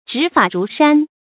执法如山 zhí fǎ rú shān
执法如山发音